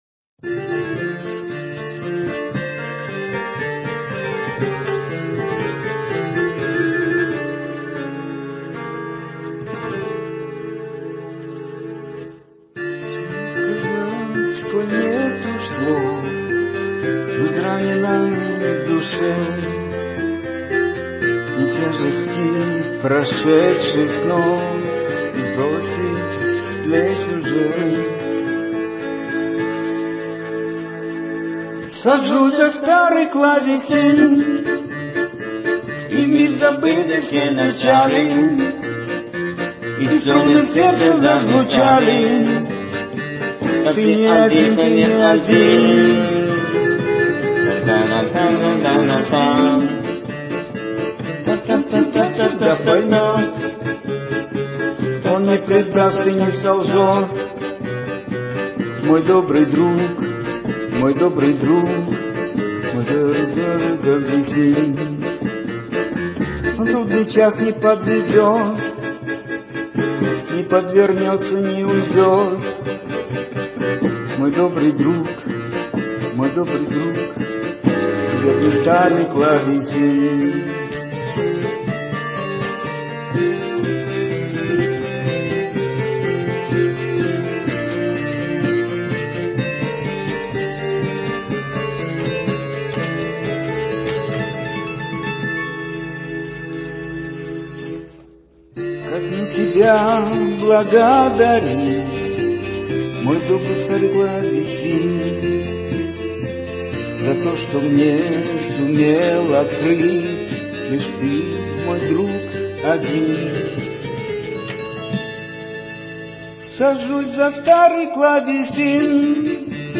пробная аранжировка